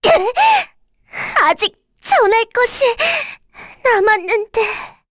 Rehartna-Vox_Dead_kr_c.wav